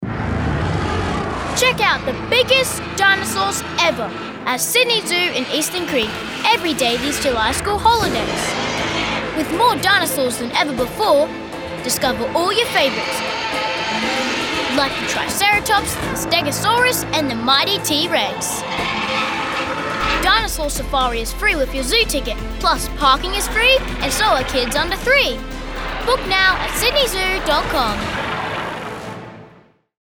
Professional Australian Male Voice Over Artists, Actors & Talent
My Australian accent has a bright, relaxed, positive and personable tone. I can be fun, friendly and engaging or I can be pretty intense and serious!